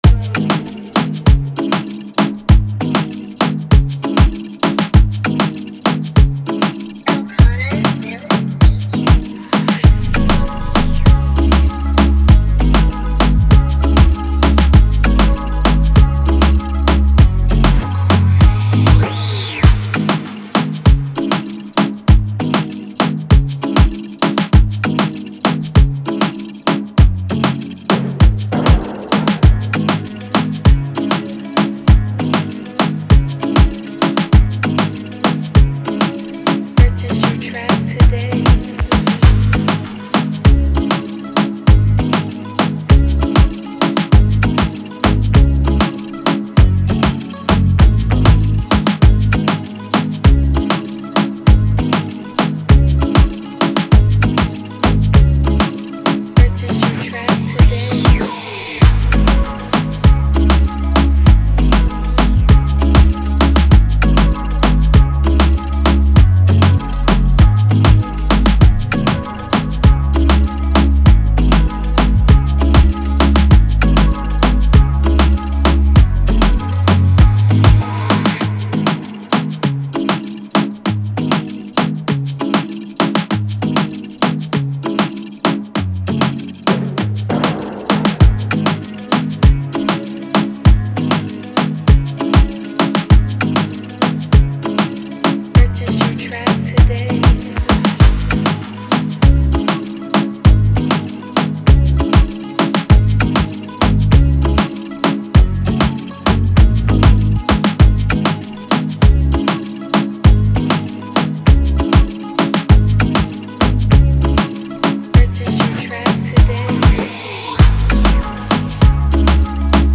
With its catchy melodies and pulsating rhythms